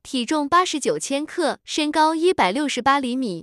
tts_result_0.wav